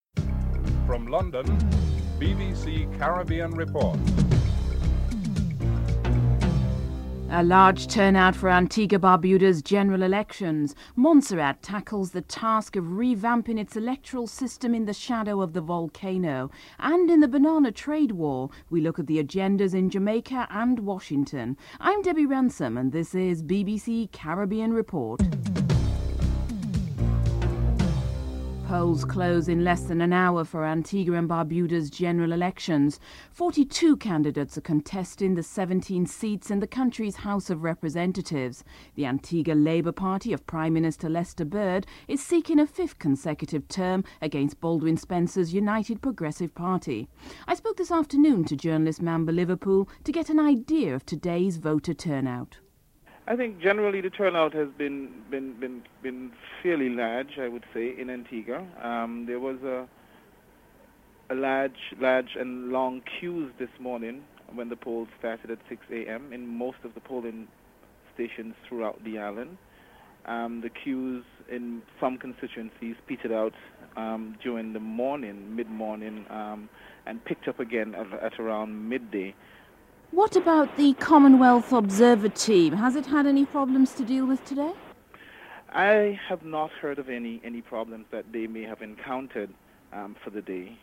Osbourne concedes defeat and discusses future transformation of his party (05:01 - 06:31)